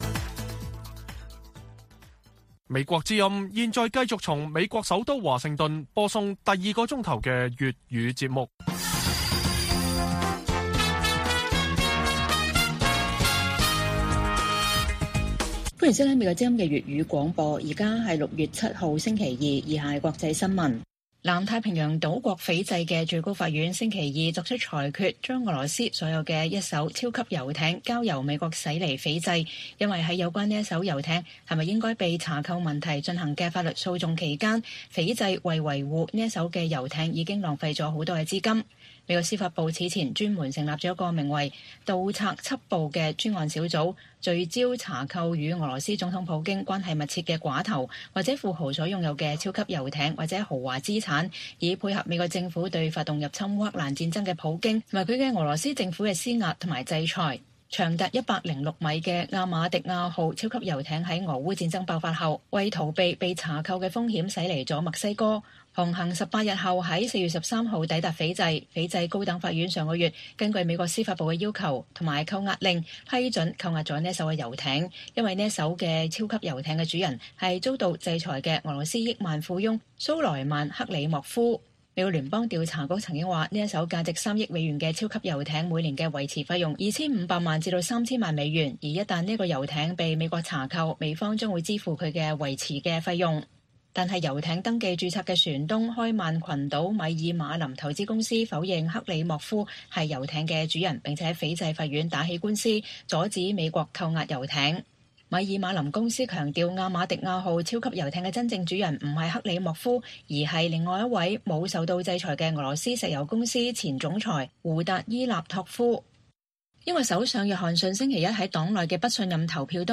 粵語新聞 晚上10-11點 : 美貿易官員：美台新貿易倡議有可能比印太經濟框架更快有成果